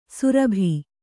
♪ surabhi